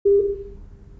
Binaural recordings of 400 Hz pure-tones played from a loudspeaker in my dining room.
That cues near the onsets of the pure-tones contribute more to spatial hearing than later cues is further evident when recordings are cropped so that they start 50 ms after each recorded tone’s onset, thereby removing cues at the tone’s onset.
Pure-tones (400 Hz) with 10 ms on/off ramps (Hanning) were played from a single loudspeaker in my dining room well above average ambient noise levels (~36 dB).